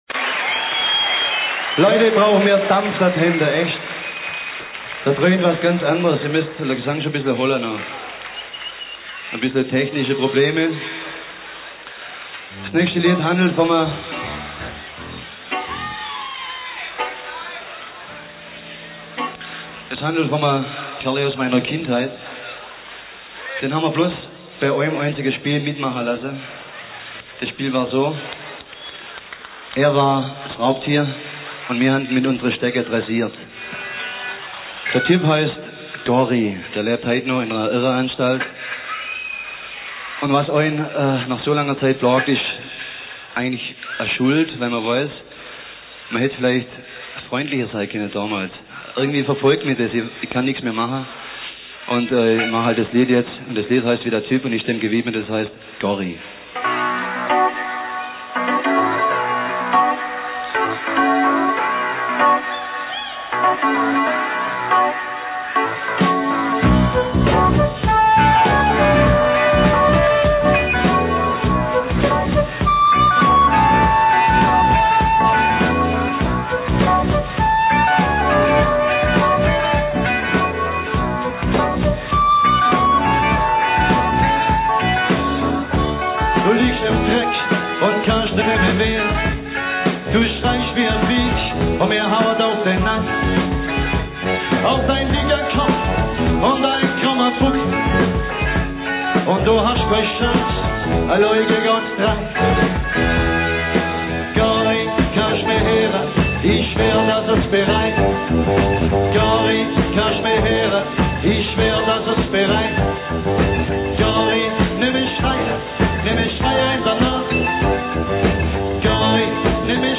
17 Titel mit diversen Stage-Annoucements.